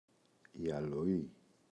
αλοή, η [aloꞋi]